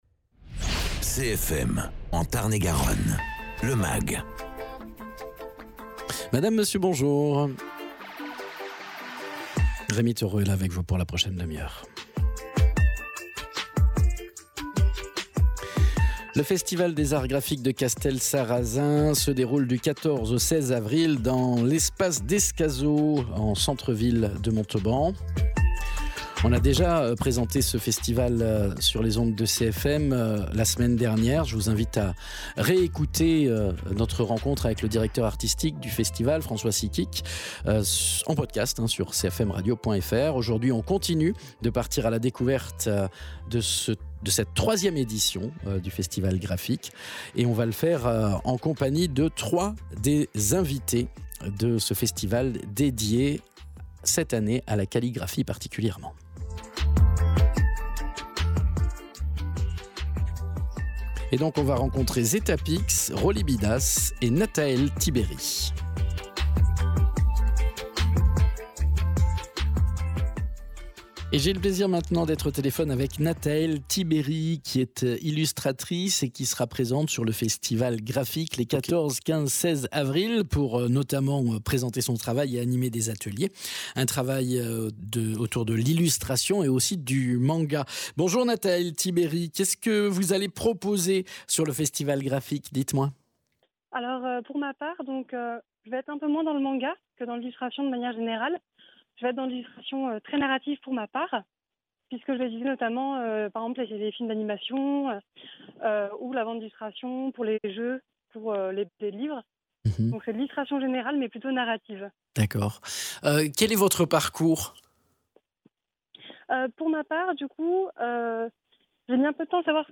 Au télephone avec trois invités du festival Grafik